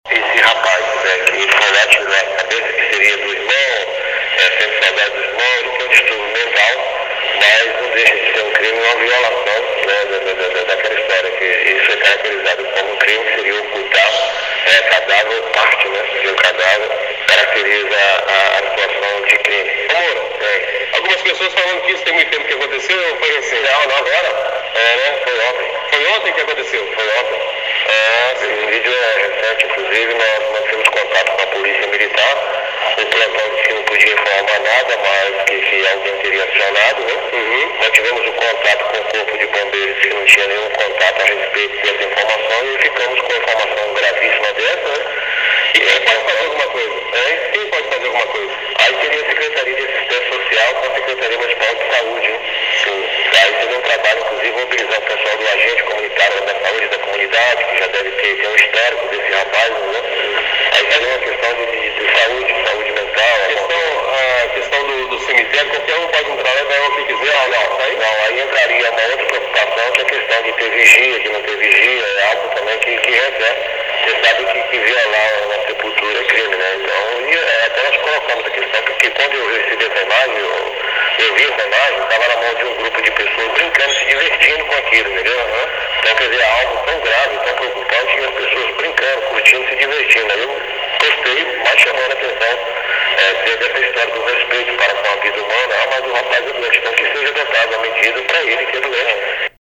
Ouça o posicionamento da imprensa local diante do caso